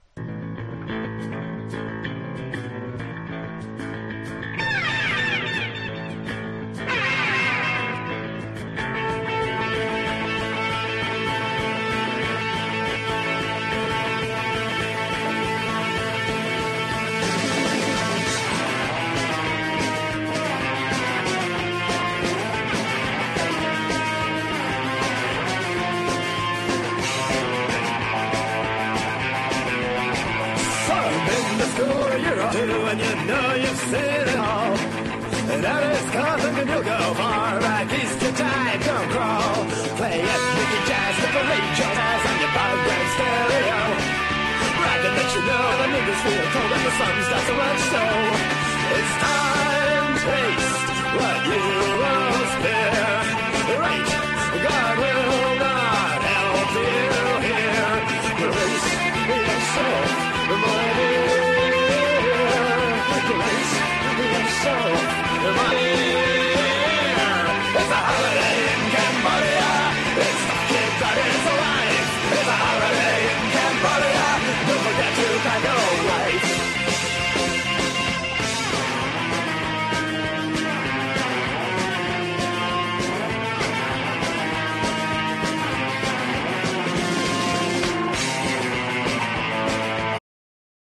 (新聞・ソノシート付き完品)：PUNK / HARDCORE